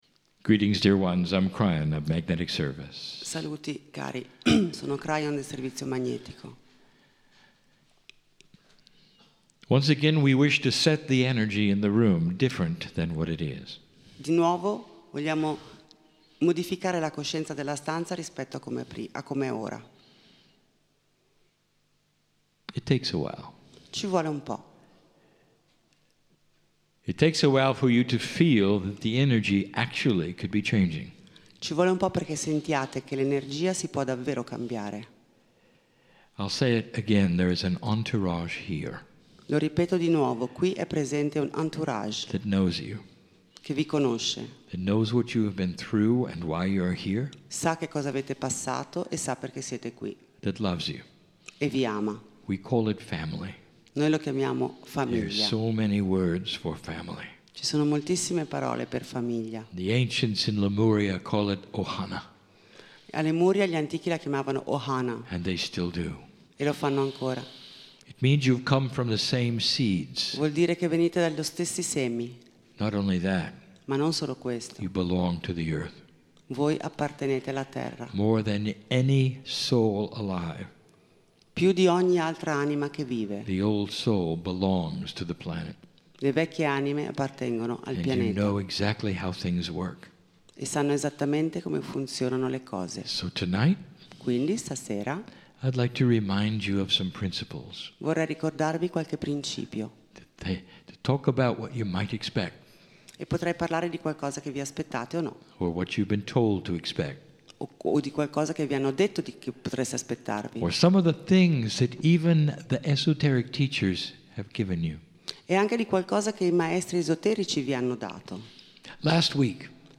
"The Four Phases" - Kryon Channelling in Catania